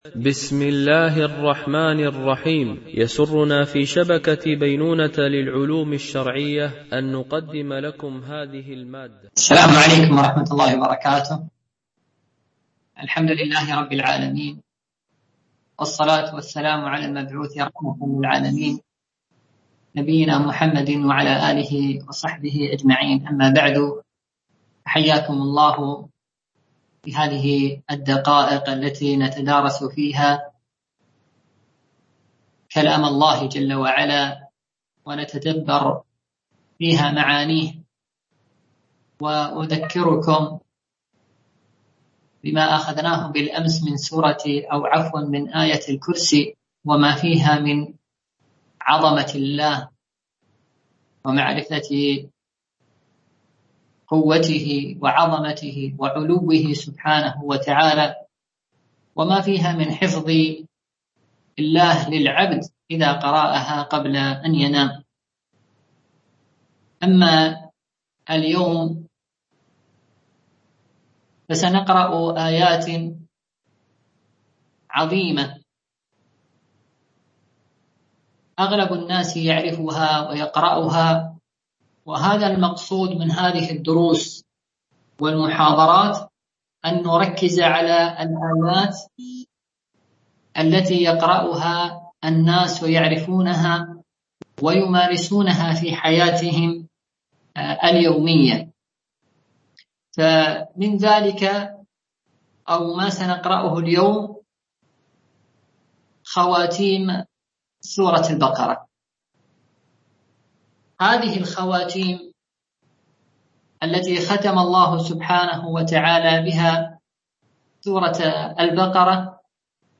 سلسلة محاضرات في تفسير القرآن الكريم - المحاضرة 3 (خواتيم سورة البقرة)